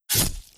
Melee Weapon Attack 15.wav